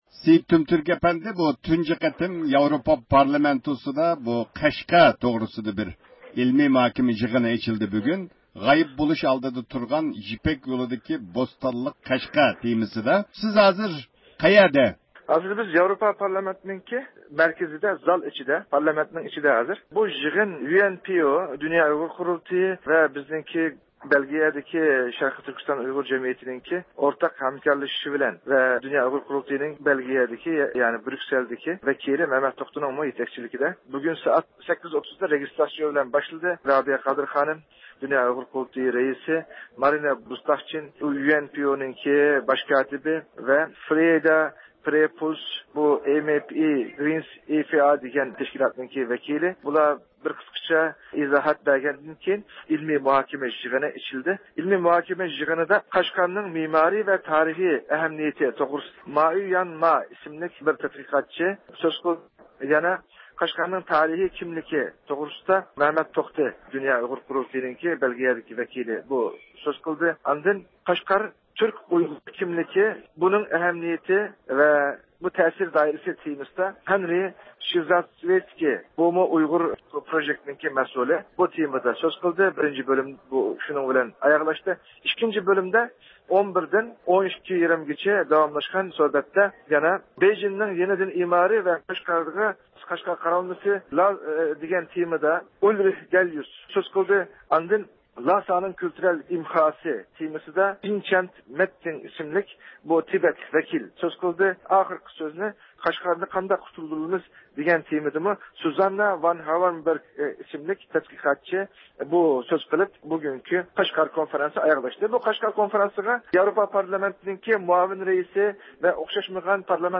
بۇ يىغىندا قانداق مەسىلىلەرنىڭ مۇزاكىرە قىلىنغانلىقىنى تەپسىلىي بىلىش ئۈچۈن نەق مەيدانغا تېلېفون قىلىپ يىغىن ئەھلى بىلەن سۆھبەت ئېلىپ باردۇق.